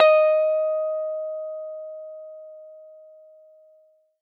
guitar-electric